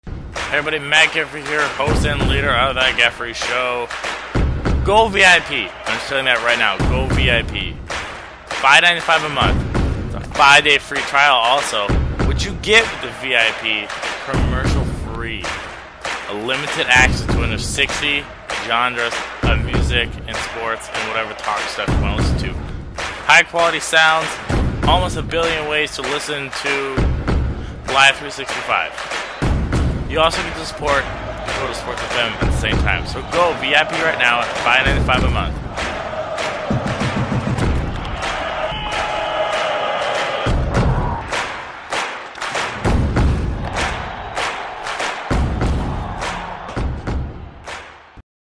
Category: Radio   Right: Commercial
Tags: Sports Radio funny podcast South Dakota NFL NBA NHL MLB Minnesota Vikings Minnesota Twins Friday Weekend Intro